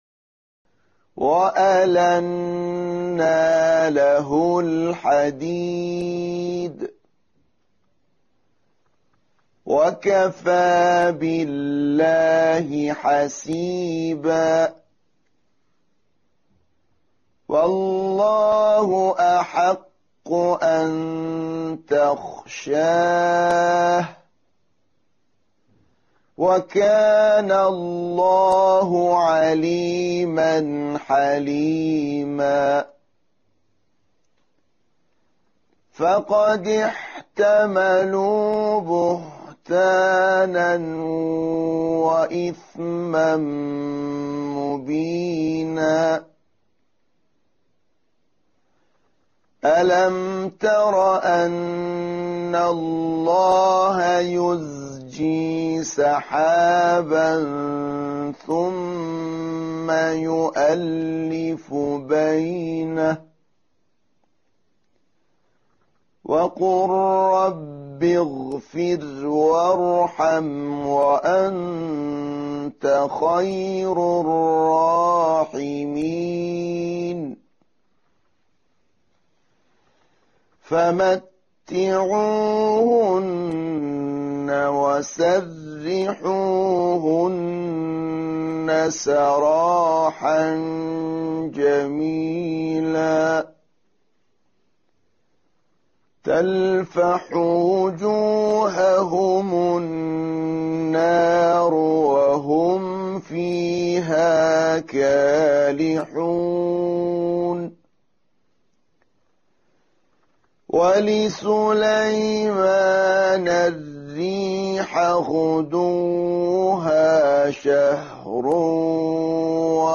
💠تلفظ حرف «ح»💠